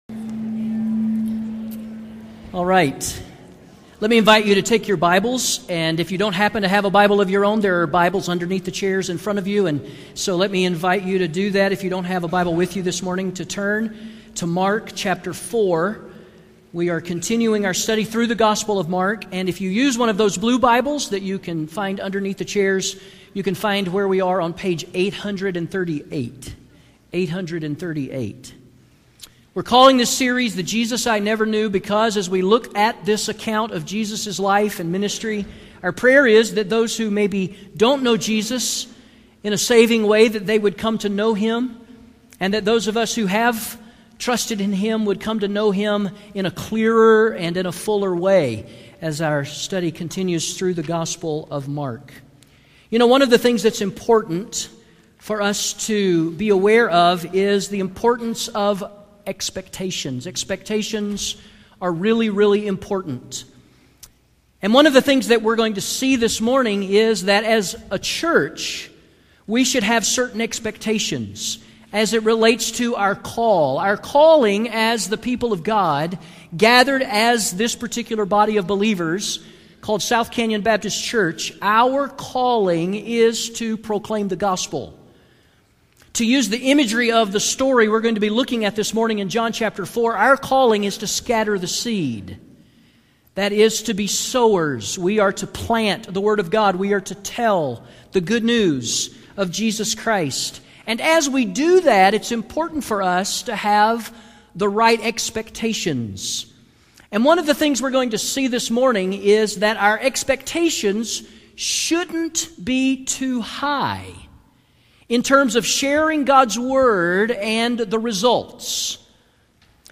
Sermons Archive - Page 28 of 33 - South Canyon Baptist Church